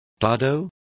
Also find out how friso is pronounced correctly.